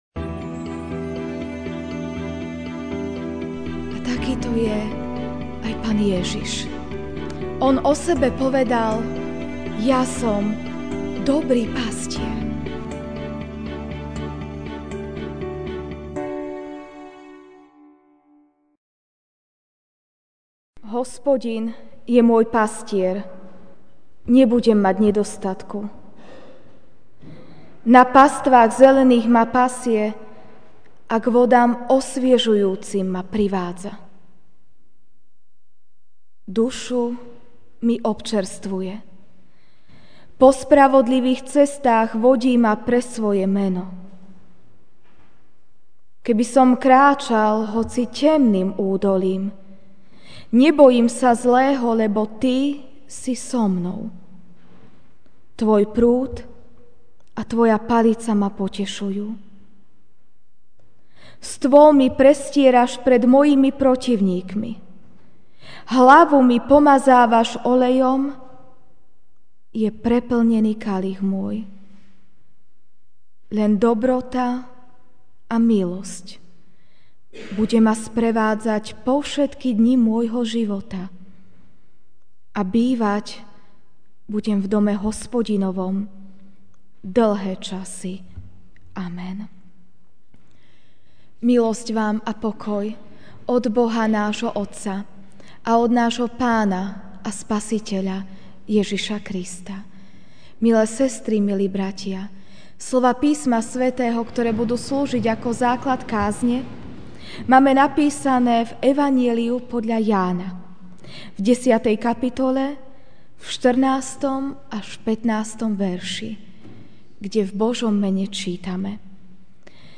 apr 10, 2016 Dobrý pastier MP3 SUBSCRIBE on iTunes(Podcast) Notes Sermons in this Series Ranná kázeň: (Ján 10, 14-15) Ja som dobrý pastier: poznám svoje a mňa poznajú moje, ako ma pozná Otec, aj ja poznám Otca, a život kladiem za ovce.